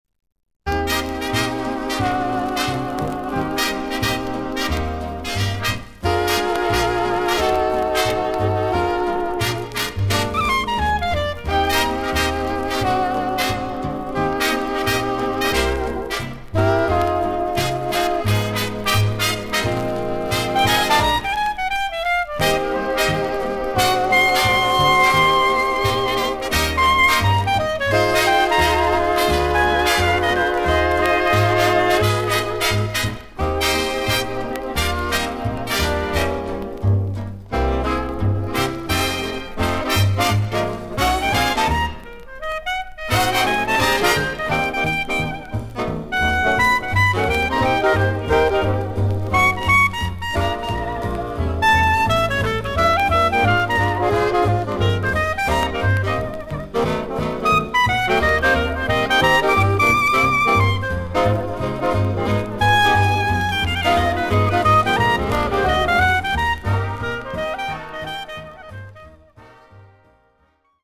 キング・オブ・スウィングと呼ばれたバンド・リーダー/クラリネット奏者。
VG+〜VG++ 少々軽いパチノイズの箇所あり。クリアな音です。